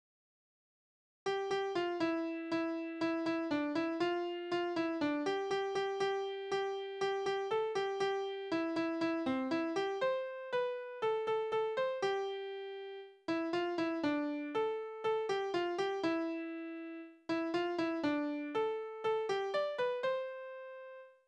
Kinderspiele
Tonart: C-Dur Taktart: 4/4 Tonumfang: große None Besetzung: vokal